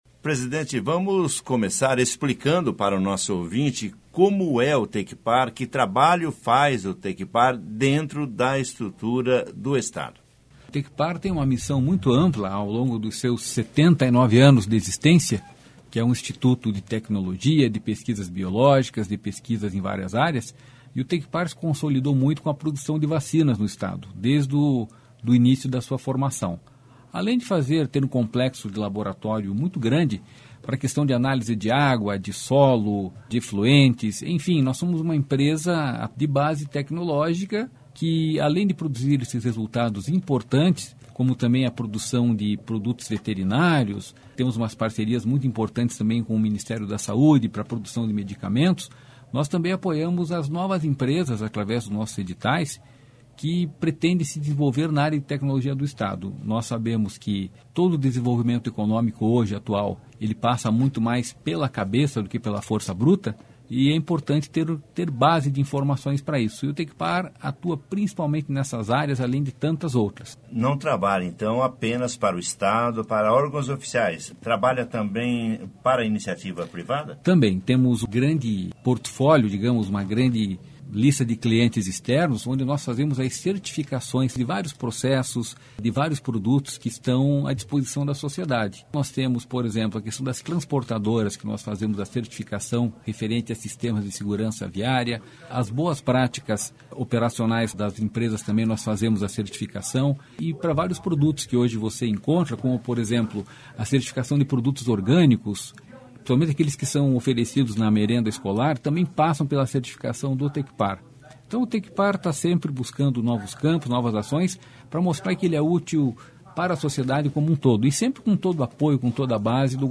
ENTREVISTA COM O PRESIDENTE DO TECPAR, JORGE CALLADO